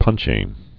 (pŭnchē)